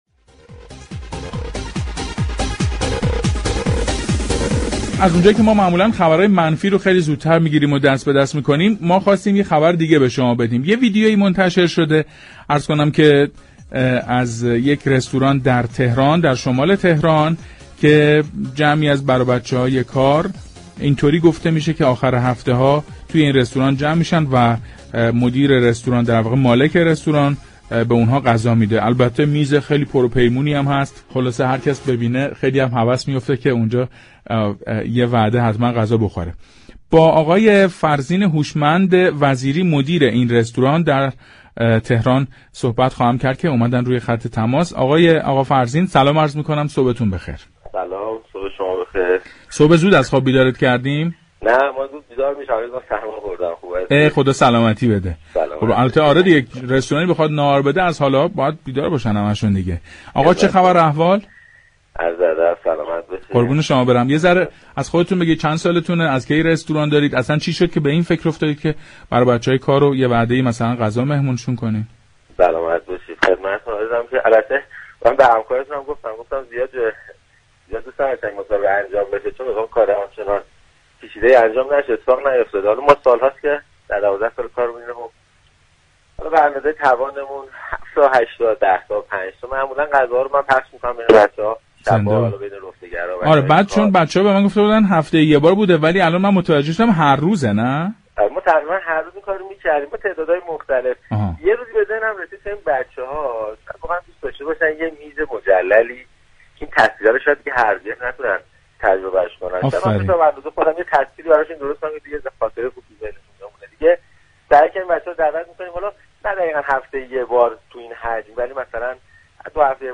رادیو تهران تمام قد به افتخار این مدیر رستوران ایستاد و در گفتگویی كه از پارك شهر پخش شد از او قدردانی كرد.